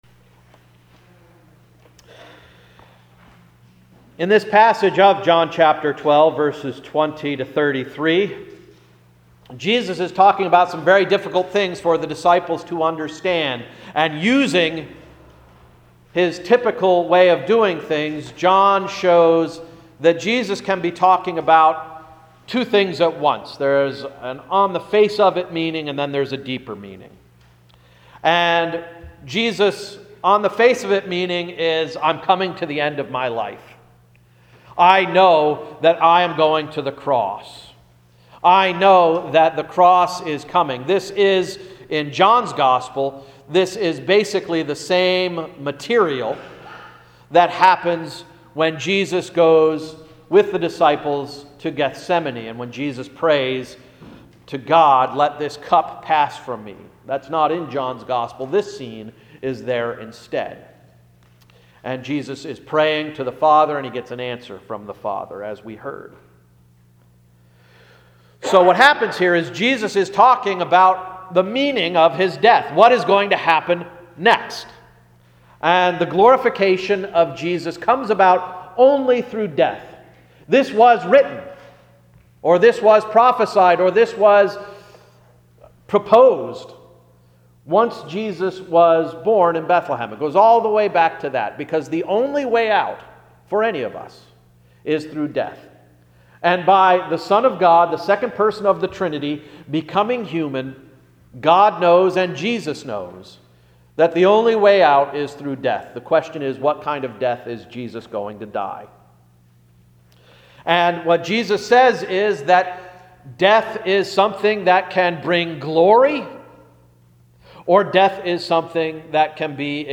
Sermon of March 22, 2015–“Salvation and Seedlots”